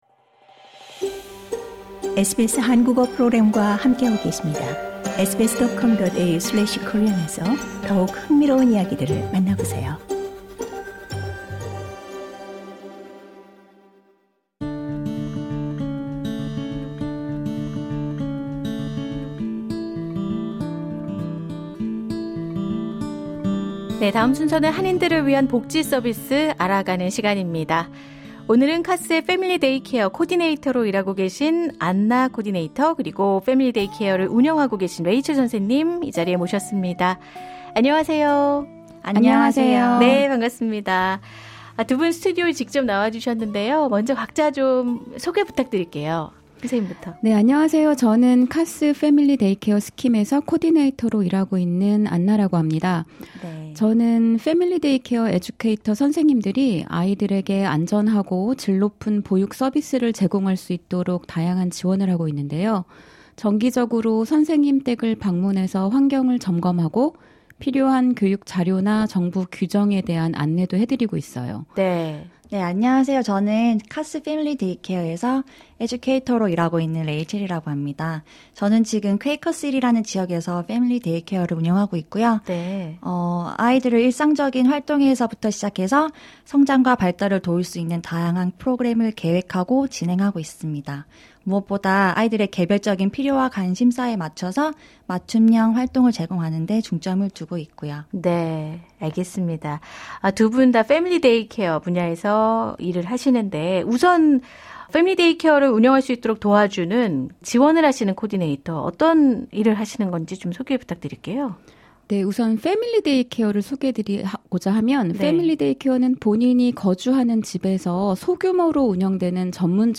두 분 스튜디오에 직접 나와 주셨는데요.